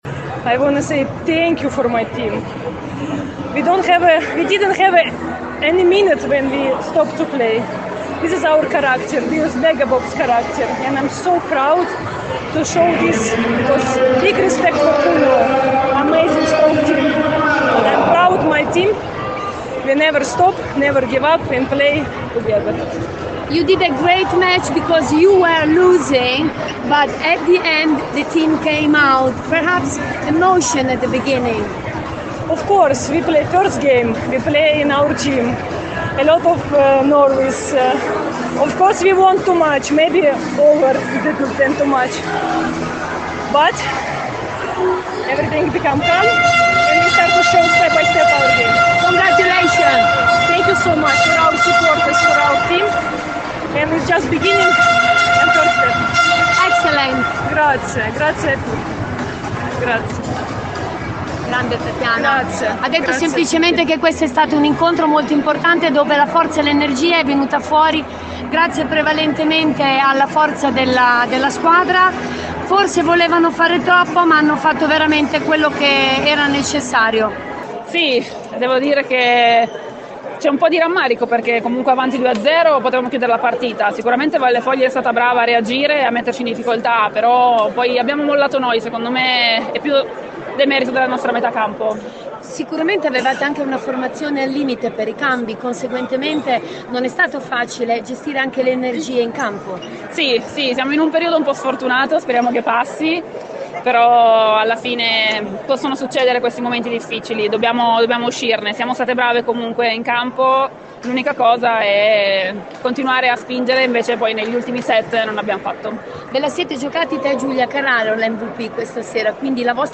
Vittoria e punti importanti, i primi conquistati per Vallefolgia, contro  Cuneo, domata per 3 set a 2. Le interviste post partita